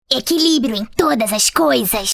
A voice line from Kennen, a champion in League of Legends, spoken in Brazilian Portuguese.